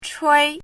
chinese-voice - 汉字语音库
chui1.mp3